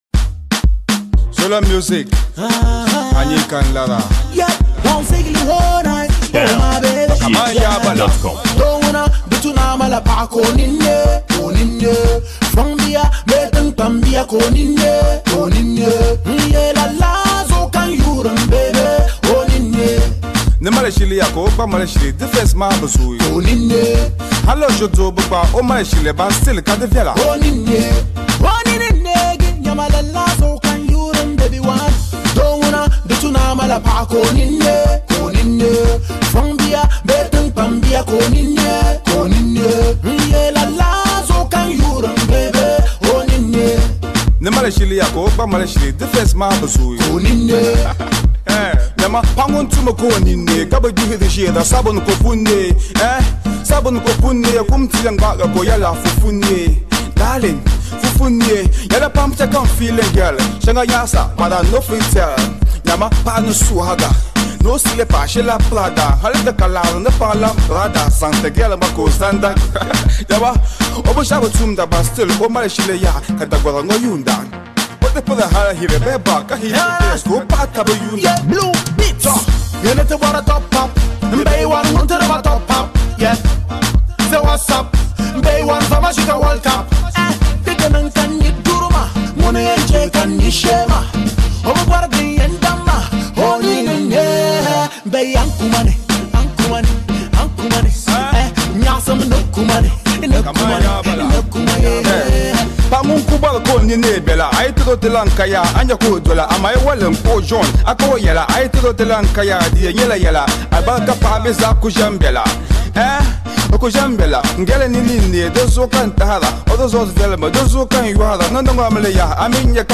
Ghana Music
presenting you with another captivating music single tagged
featured singer